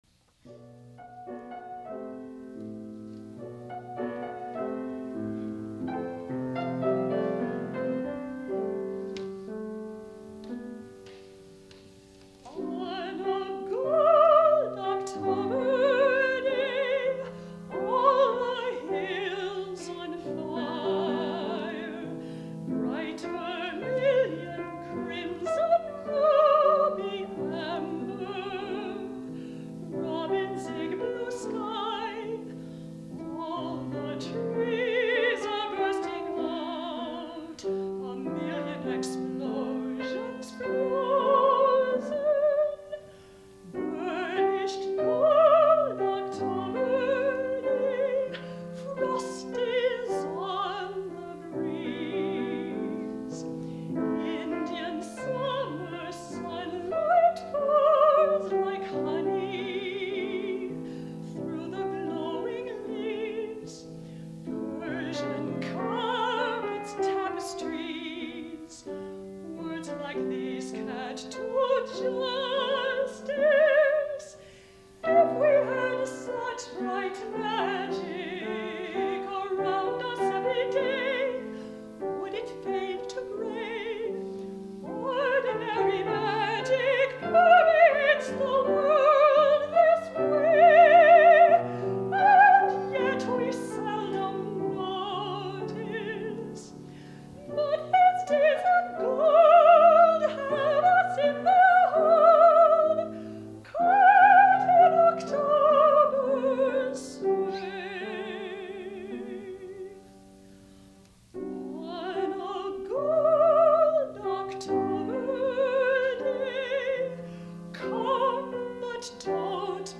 soprano
piano in concert at St. Anselm College